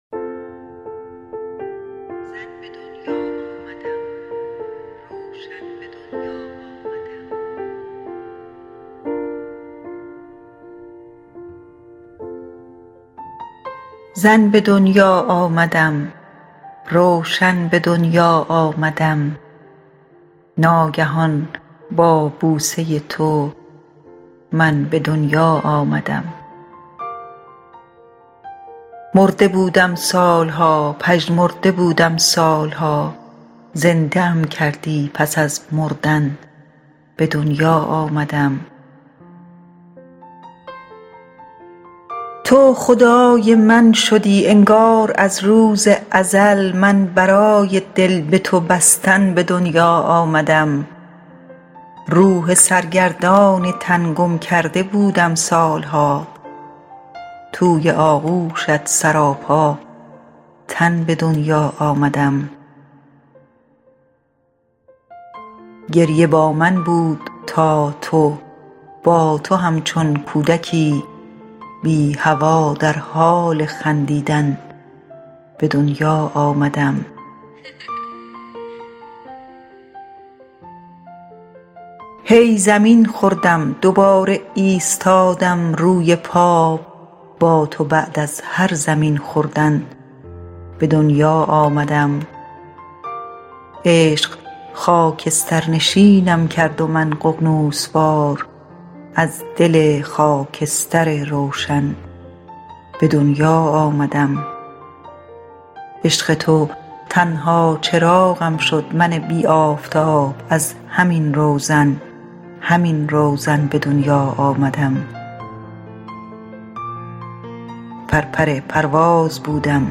میکس و مسترینگ